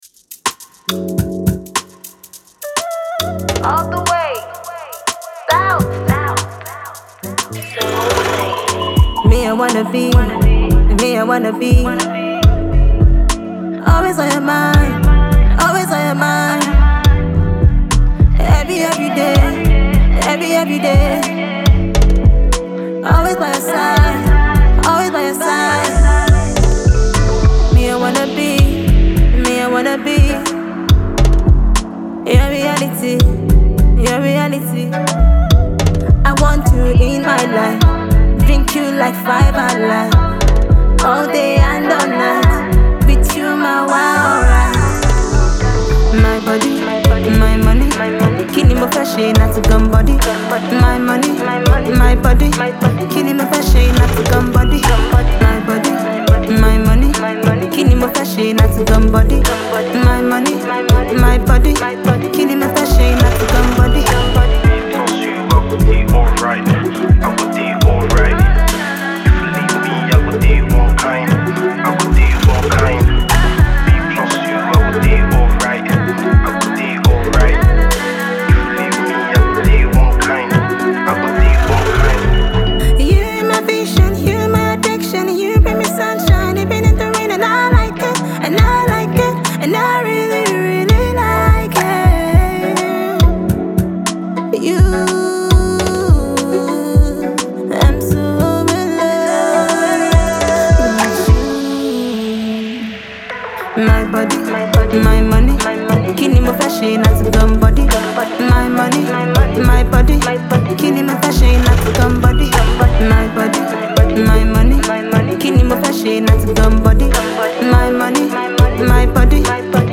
is a seductive and enticing song